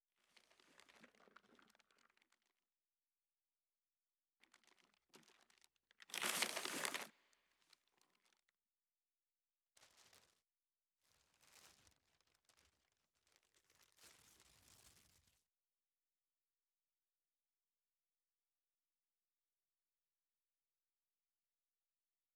环境音
04_书店内_奶奶写字.wav